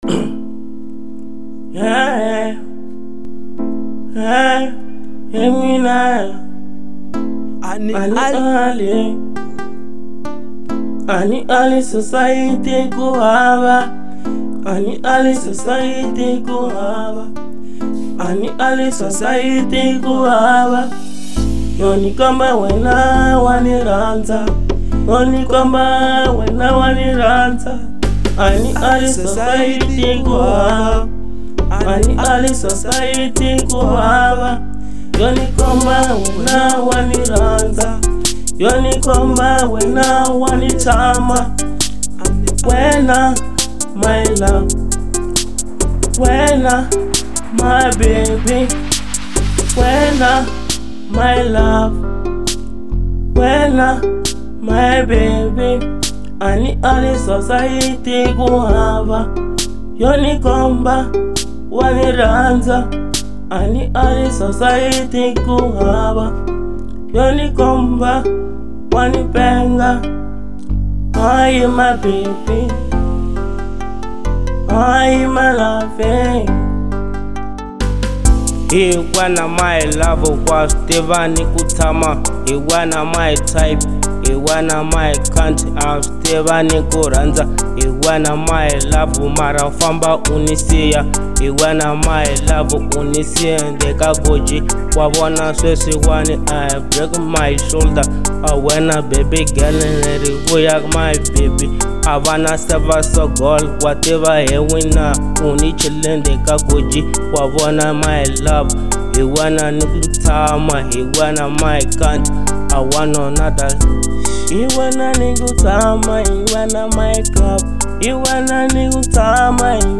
03:49 Genre : Trap Size